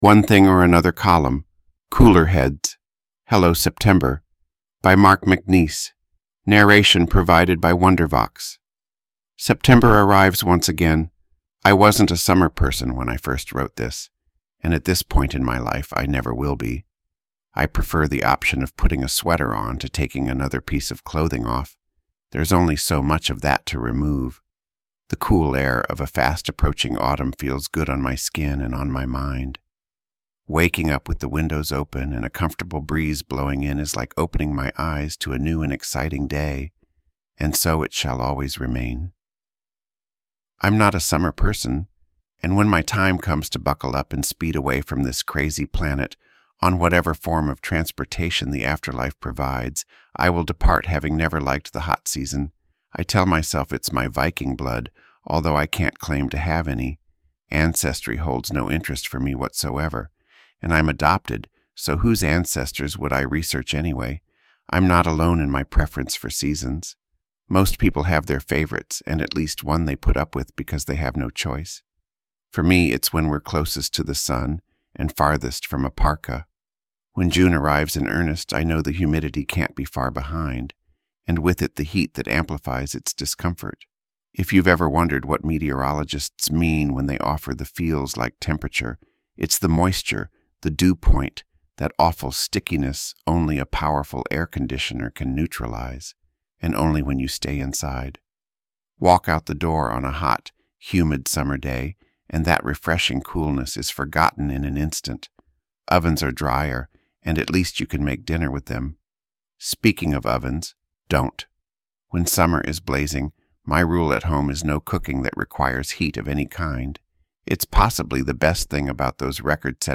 Narration provided by Wondervox.